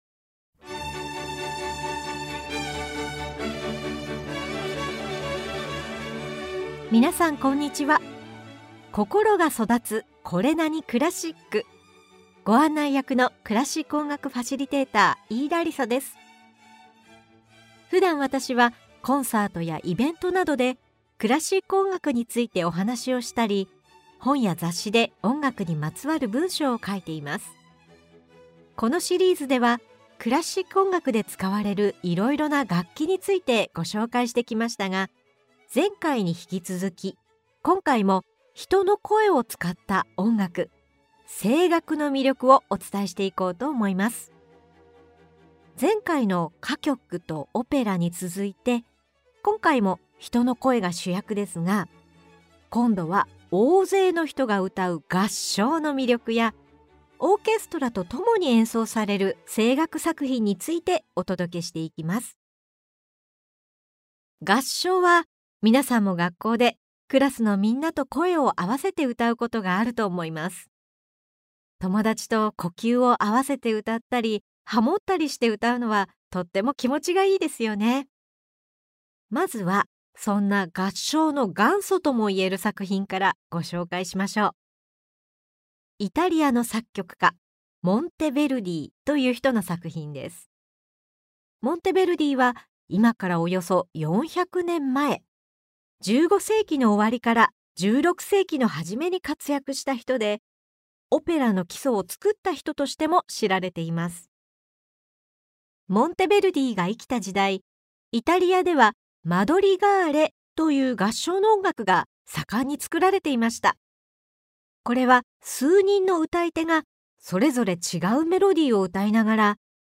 実際に曲を聴きながら、いろんな楽器とその音色に親しんでください。
今回の「声楽（2）」では、大勢の人が歌う「合唱」、オーケストラと共に演奏される声楽作品を聞きながら、その魅力を紹介します！